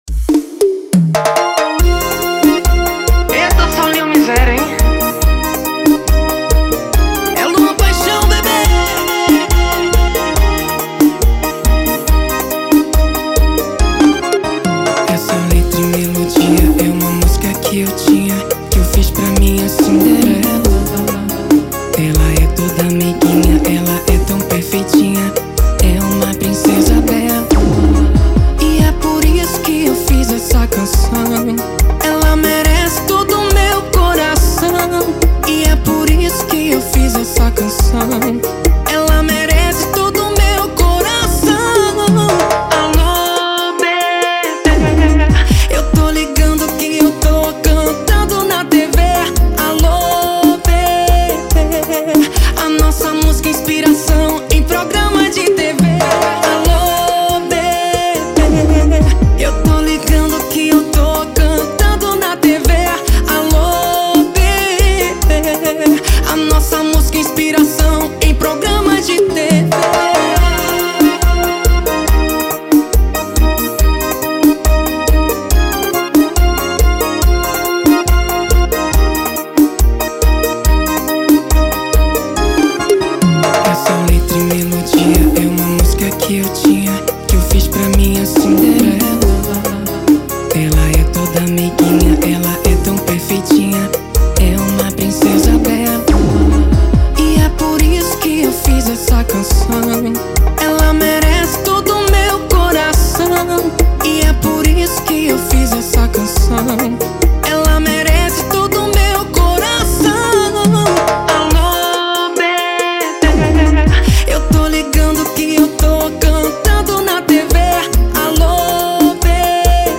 EstiloArrocha